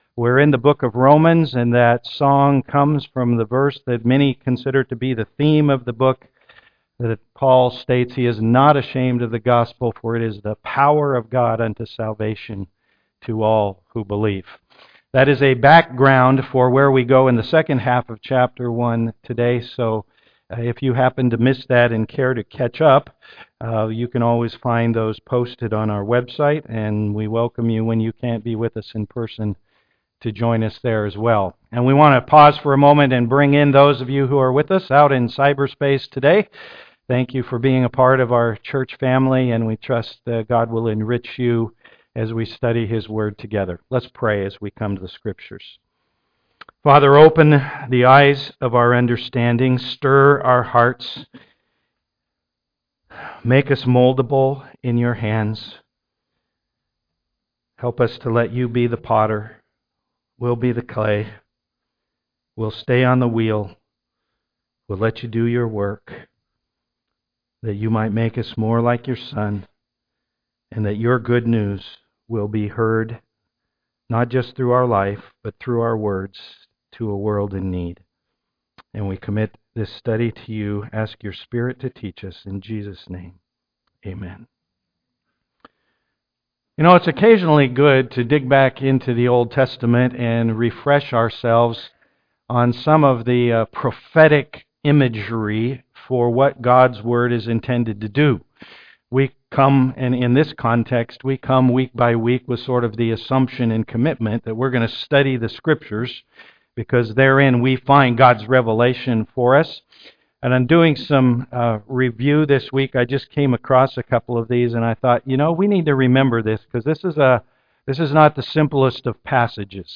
Romans 1:18-32 Service Type: am worship If God is perfectly righteous